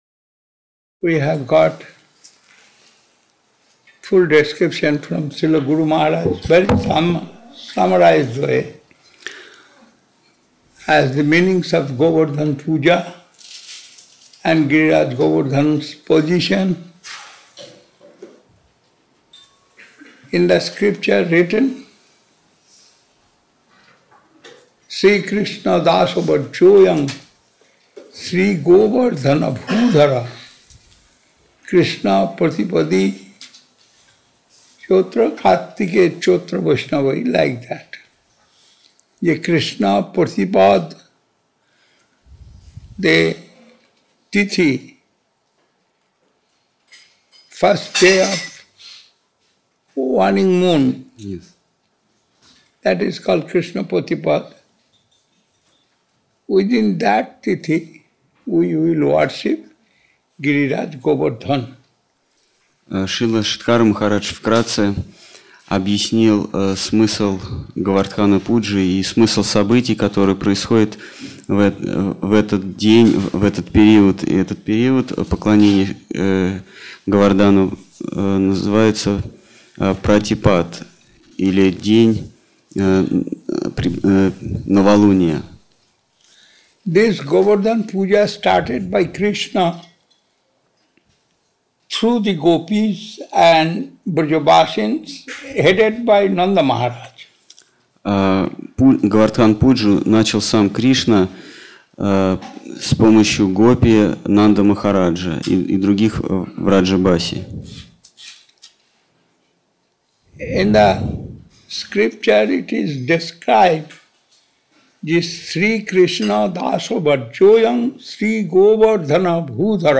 Киртан.
Place: Sri Chaitanya Saraswat Math Saint-Petersburg